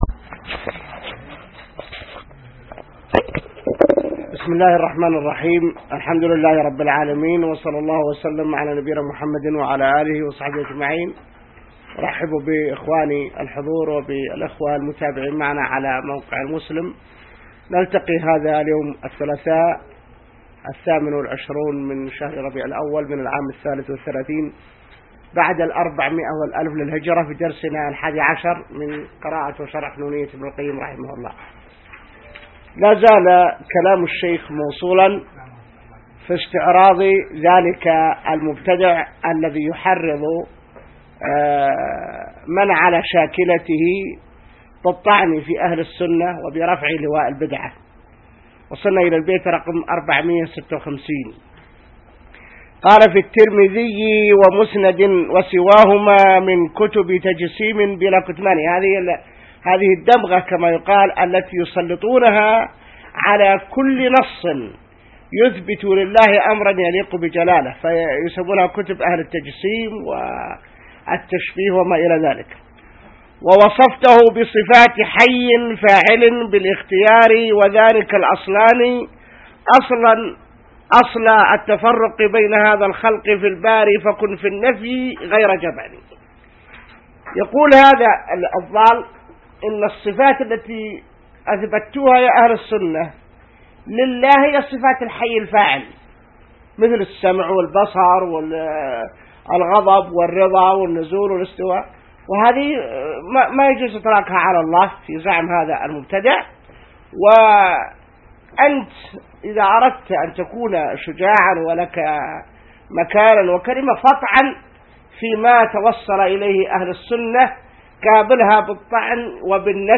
الدرس 11 من شرح نونية ابن القيم | موقع المسلم